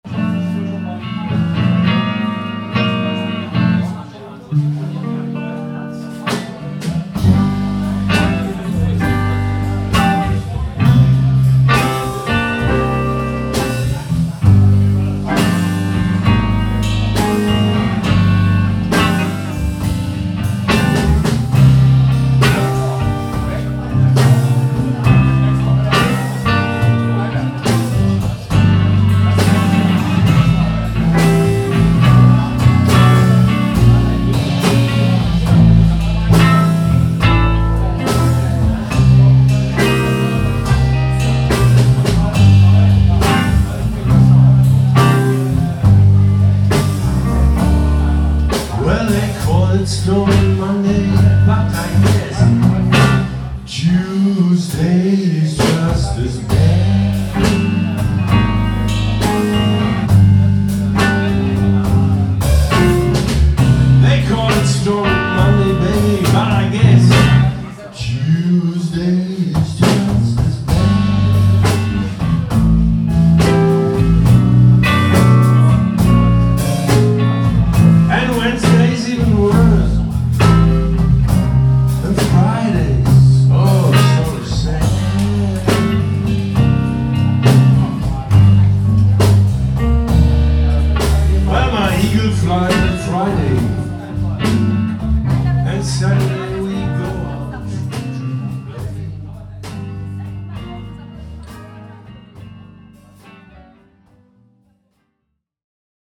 … is a Blues band from Cologne, Germany:
Guitar
Drums
Vocals & Open-D-Slide/Bass-Guitar.
plays Delta, Country & Rhythm Blues
It ties in with the 1940s and 50s.